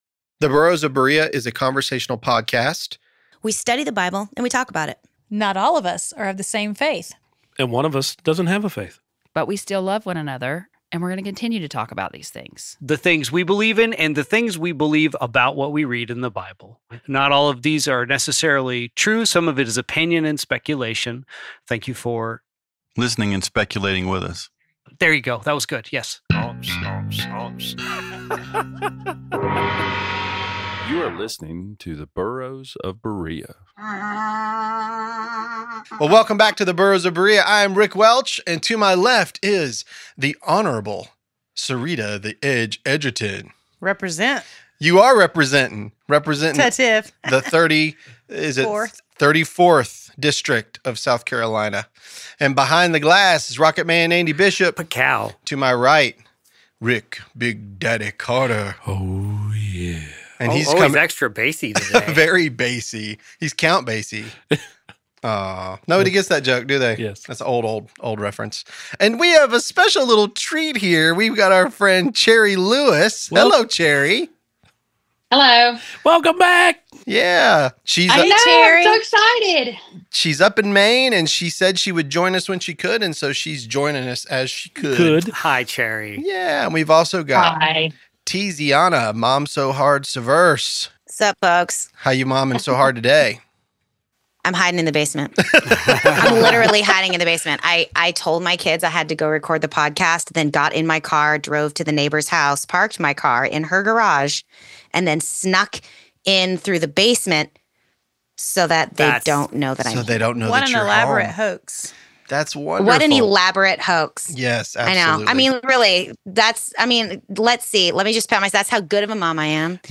The Burros of Berea is a conversational podcast. We study the Bible and we talk about it.